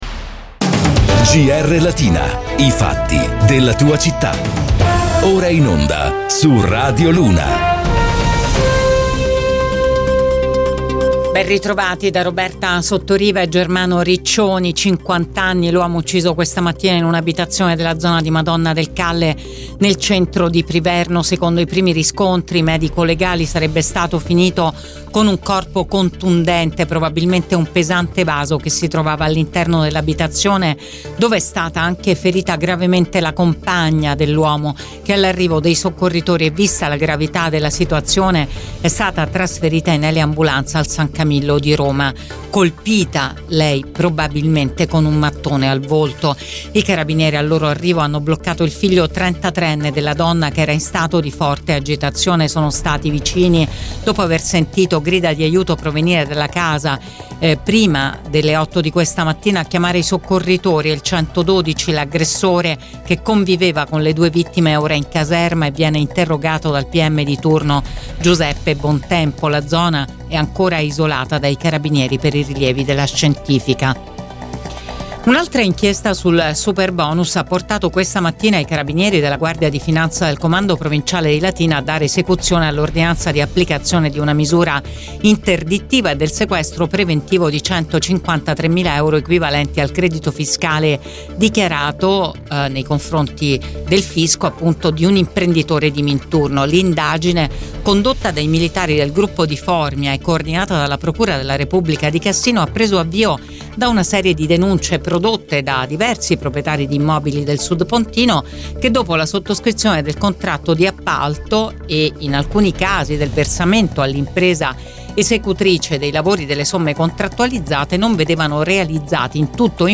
LATINA – Qui puoi ascoltare il podcast di GR Latina in onda su Radio Immagine, Radio Latina e Radio Luna